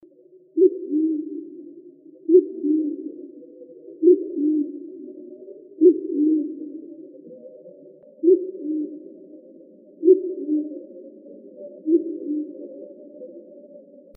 bandtailedpigeon.wav